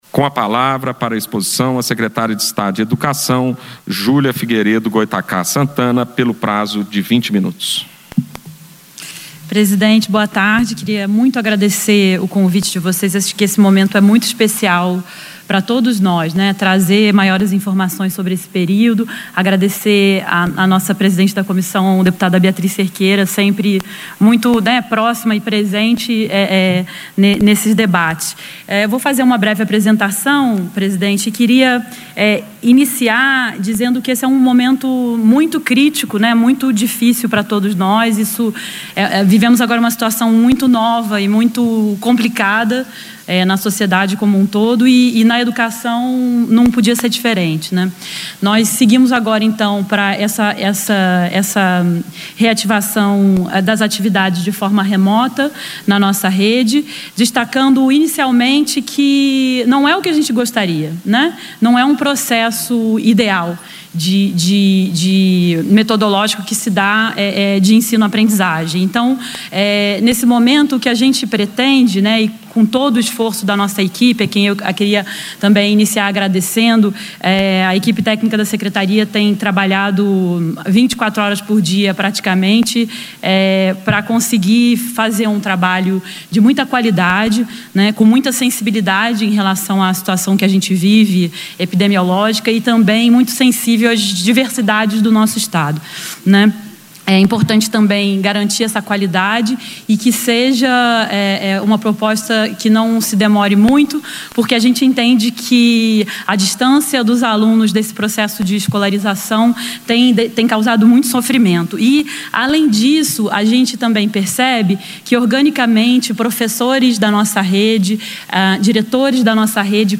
O pronunciamento, durante audiência no Plenário, esclarece posição do Estado ao convocar professores ao trabalho, para implantar as aulas a distância na educação estadual enquanto perdura a pandemia da Covid-19.
Discursos e Palestras